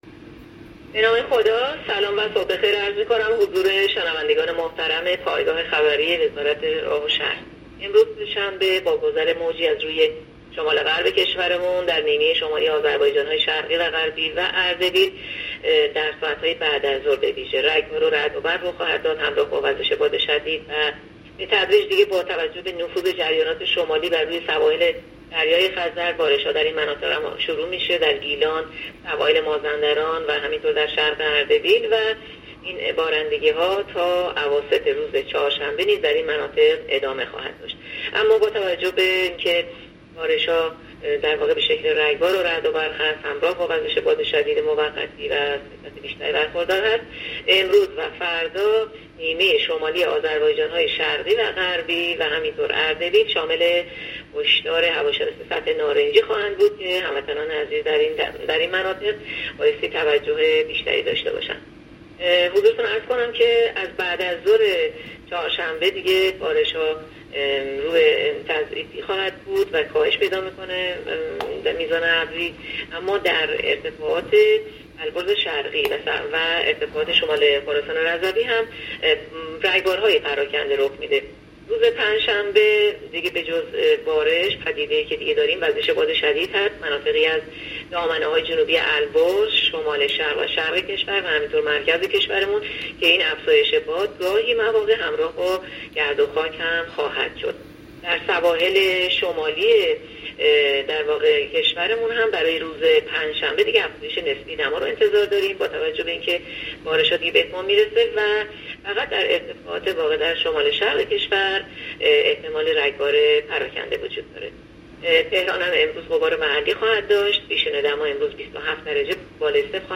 گزارش رادیو اینترنتی پایگاه‌ خبری از آخرین وضعیت آب‌وهوای ۲۹ مهر؛